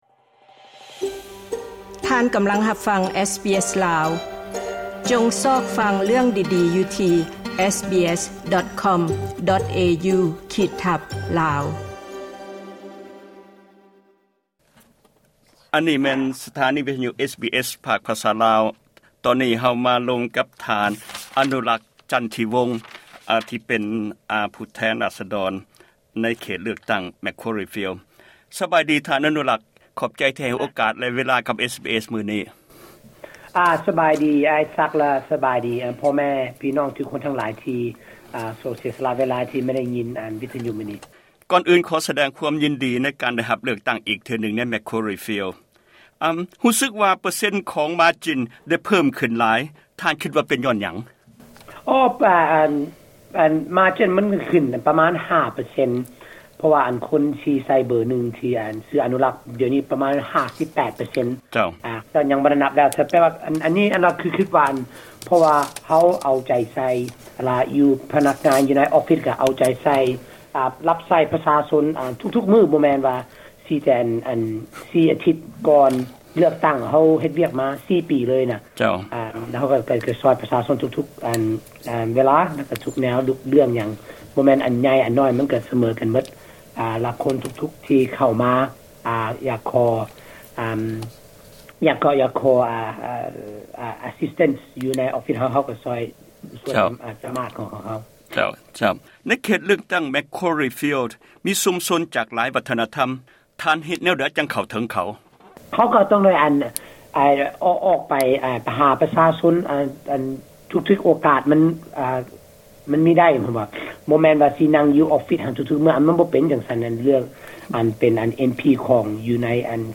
Mr. Anoulack Chanthivong was re-elected as an MP for Macquarie Fields with an increase of more than 5% margin. SBS Radio, Lao Programs had an opportunity to have a “chat” with him. This is not an interview as we talked informally. Many areas had been covered: from (a bit of) his childhood to his journey into politics and everything else in between as the questions were not planned.